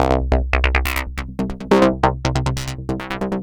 tx_synth_140_flttrigger_C.wav